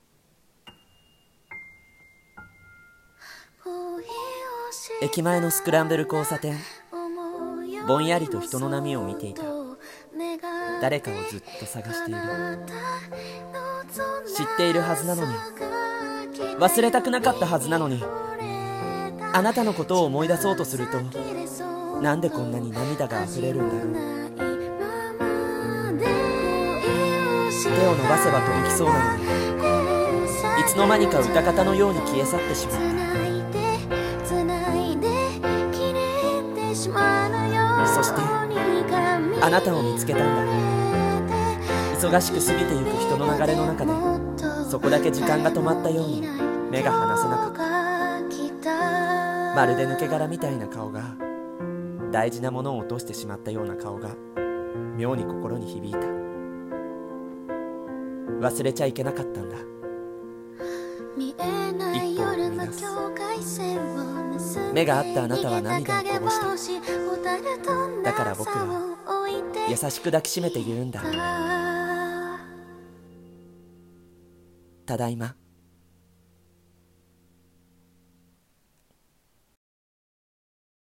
声劇】ただいま。